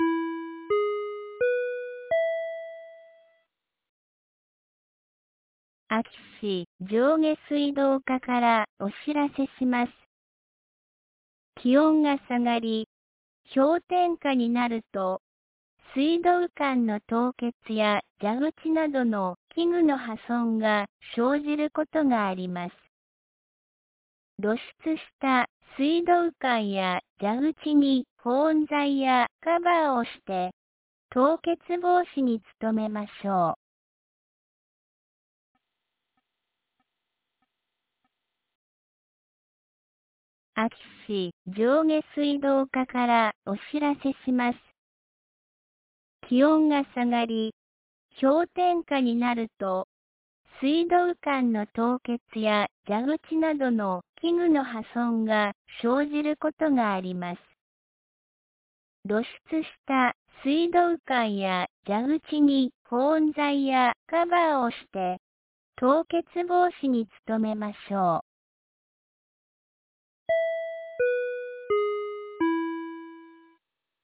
2025年02月05日 17時31分に、安芸市より全地区へ放送がありました。
放送音声